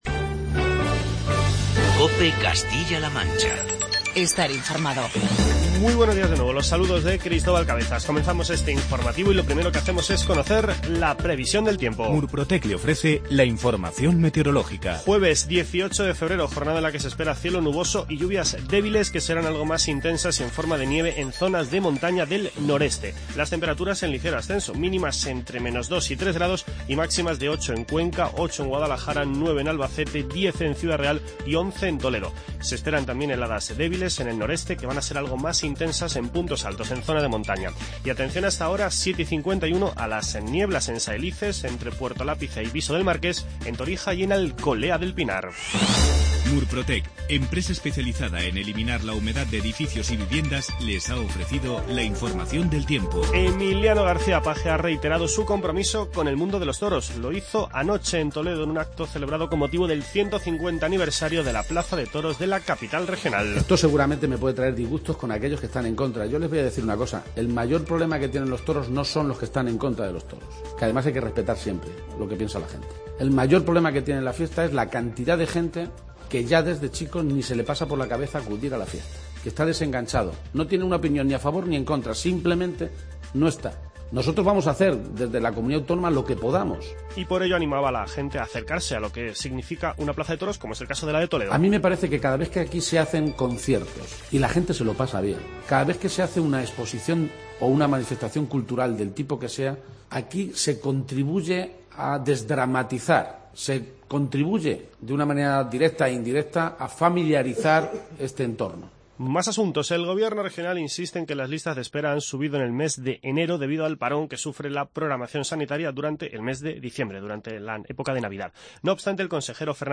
Informativo regional
García-Page reitera su compromiso con el mundo de los toros. Declaraciones del presidente de la Junta con motivo de la celebración del 150 aniversario de la plaza de toros de Toledo.